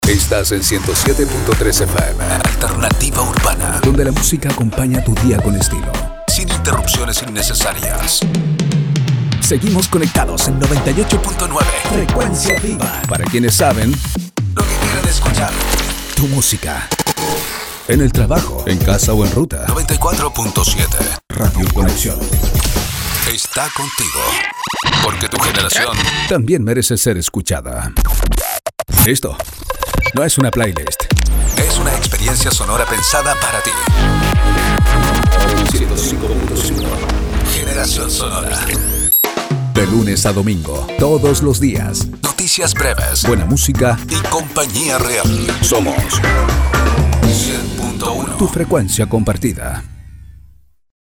Male
Adult (30-50)
Locutor versátil, desde lo formal a lo informal, pasando por personales hasta locuciones sofisticadas, solo bastará un buen guion/ reff para comprender su proyecto y darle forma, principalmente soy una voz grave.
Corporate
Continuidad Para Radios